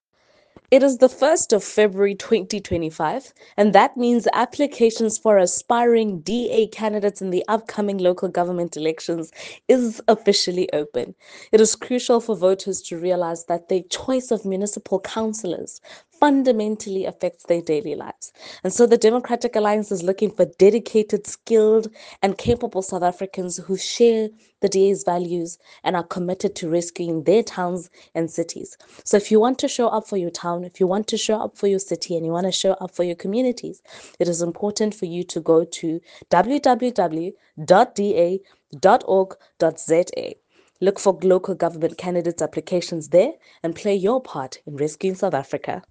soundbite by Karabo Khakhau MP.